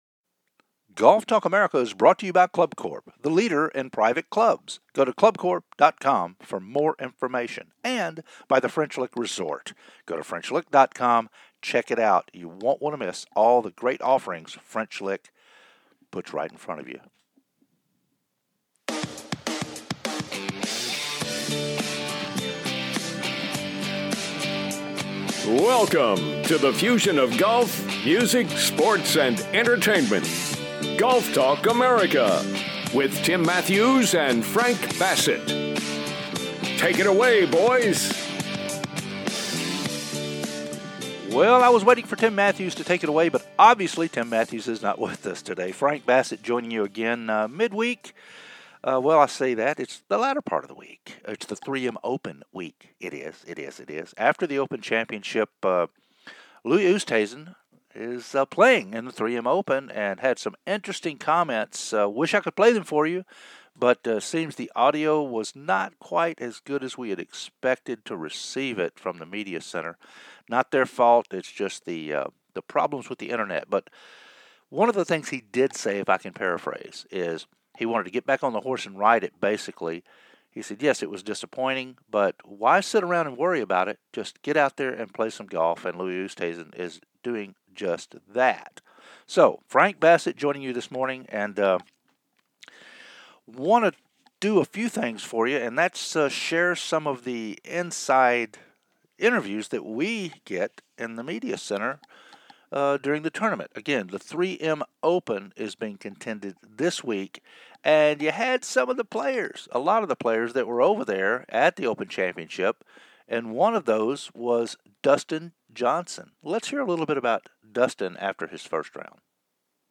"LIVE INTERVIEWS" FROM THE MEDIA CENTER AT THE 3M OPEN